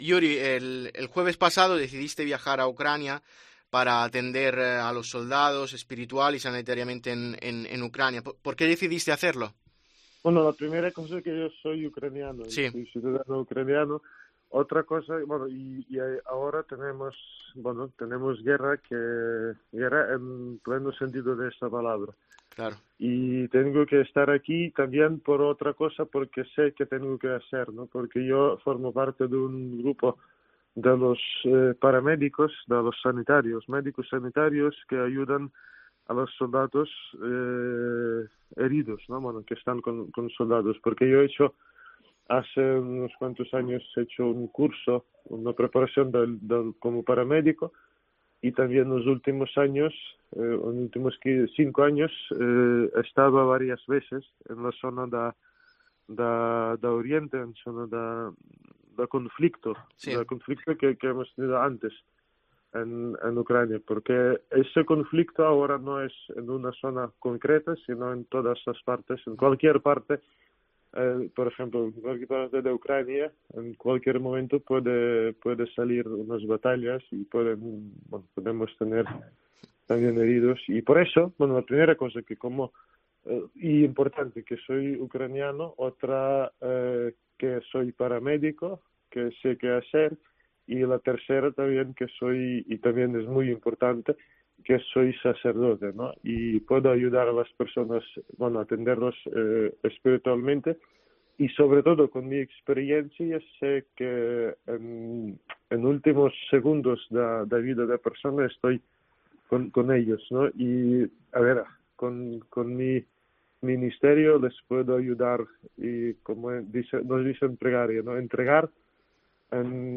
ECCLESIA ha conseguido hablar con él para saber en primer lugar cómo está y cuál es la situación en su país.